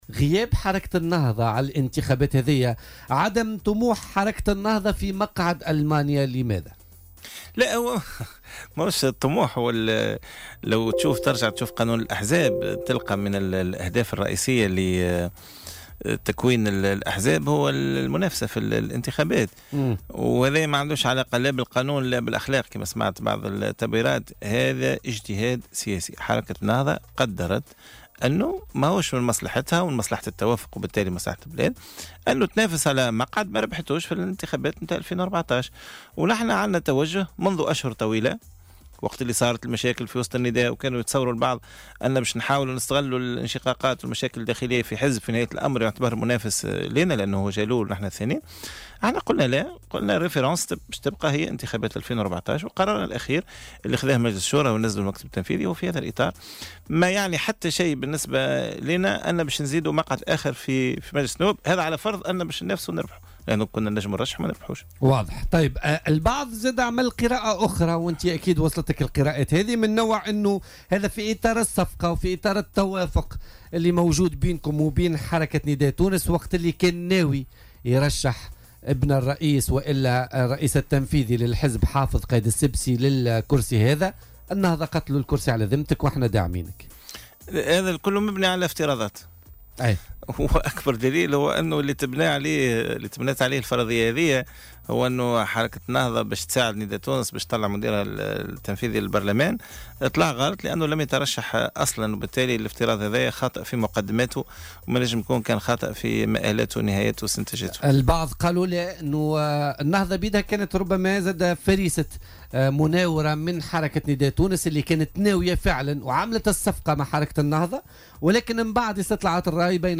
وأضاف ديلو، ضيف برنامج "بوليتيكا" اليوم الثلاثاء أن كل ما قيل حول وجود صفقة بين النداء والنهضة لفسح المجال أمام مرشح النداء حافظ قائد السبسي، تبيّن أنه مجرد افتراضات خاطئة، مشيرا إلى أن القرار اتخذته الحركة بناءا على اجتهاد خاص، دون ضغط أو إكراه من أي جهة"، وفق تعبيره